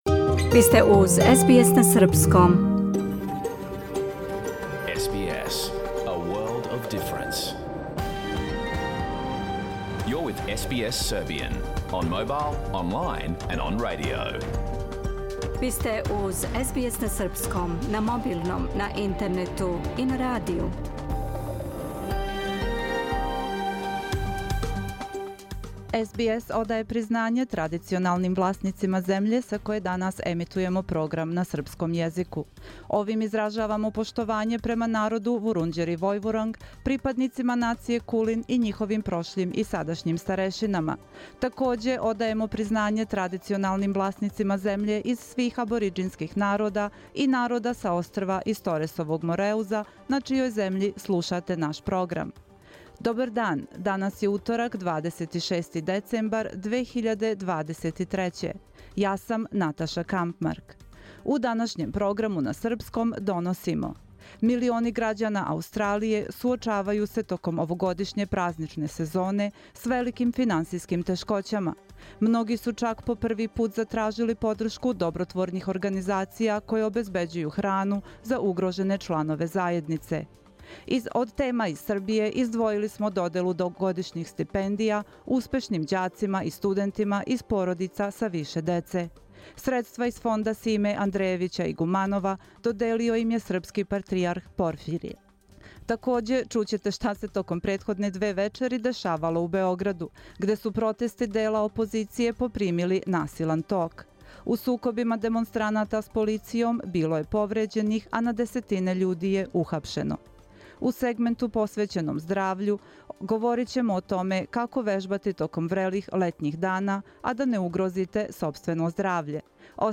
Програм емитован уживо 26. децембра 2023. године
Уколико сте пропустили данашњу емисију, можете је послушати у целини као подкаст, без реклама.